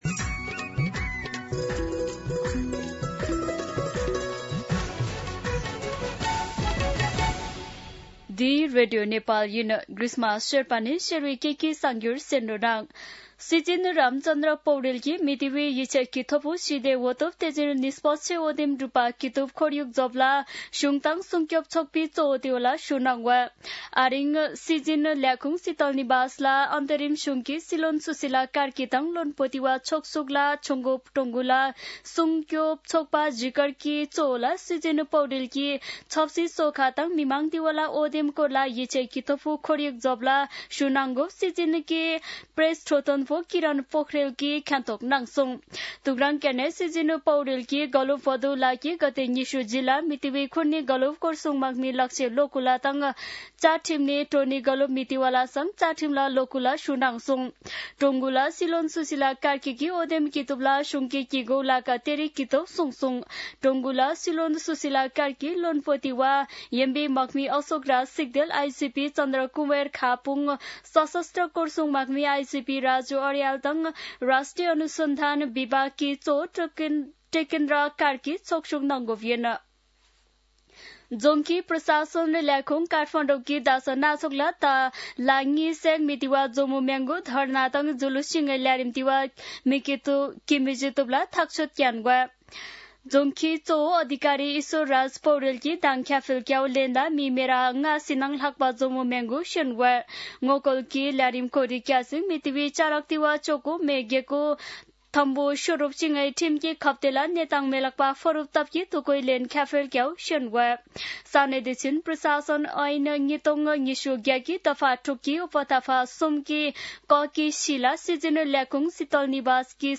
शेर्पा भाषाको समाचार : १ कार्तिक , २०८२
Sherpa-News-6.mp3